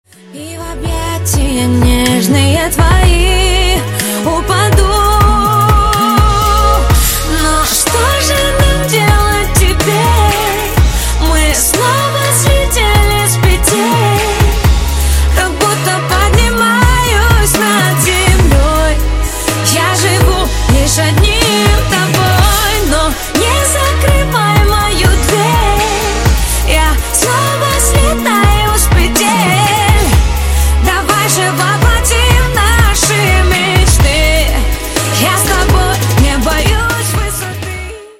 поп
красивые
спокойные
RnB